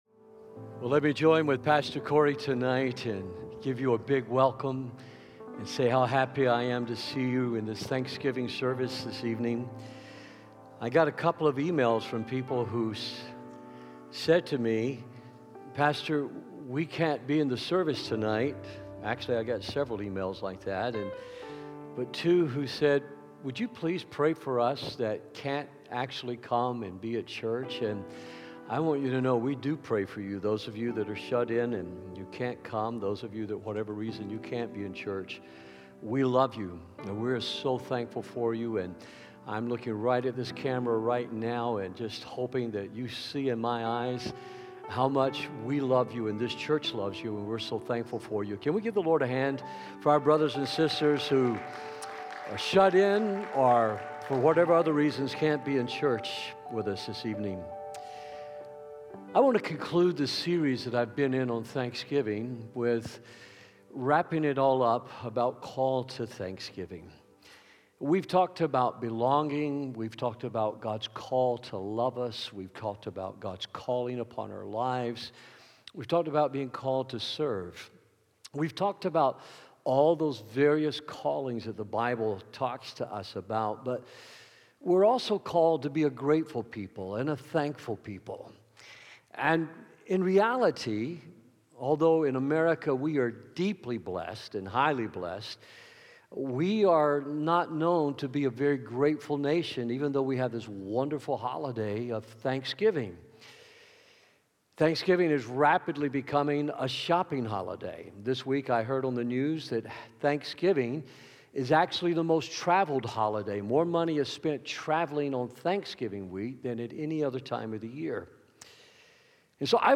In tonight's Thanksgiving Service